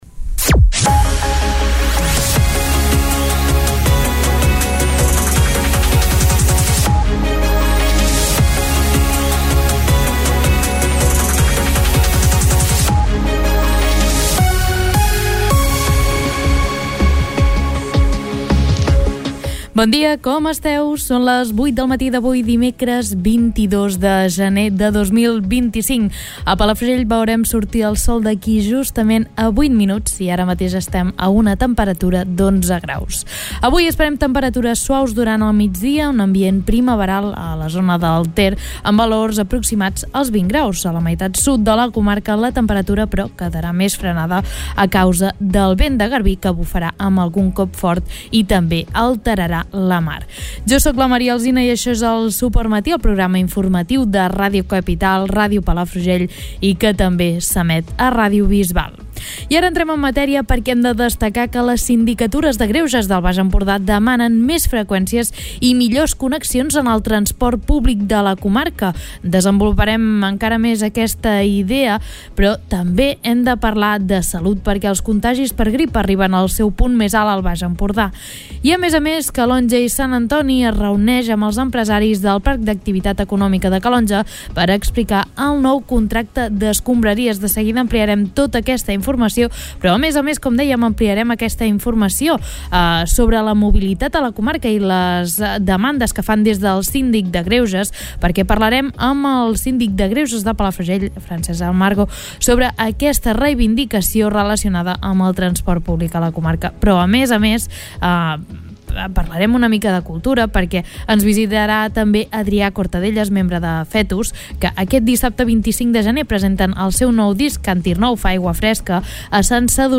Escolta l'informatiu d'aquest dimecres